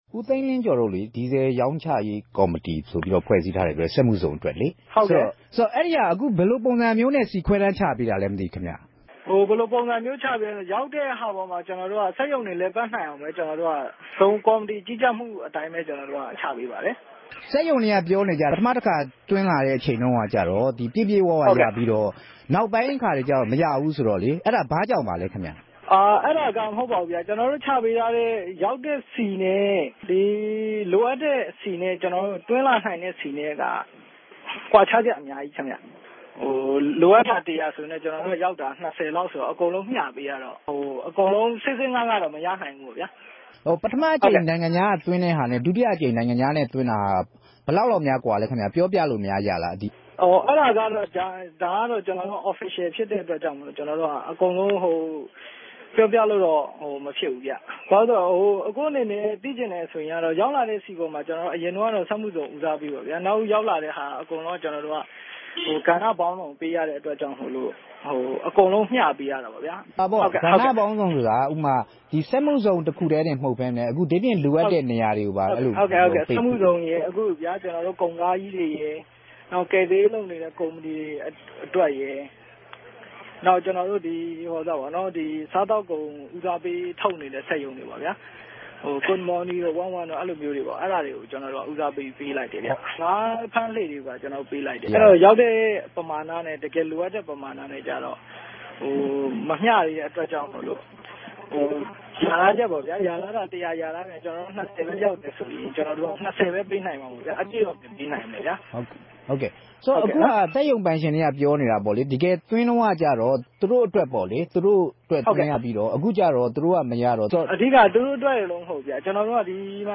ဆက်သြယ် မေးူမန်းခဵက်။